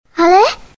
baobao_nan_xiao.mp3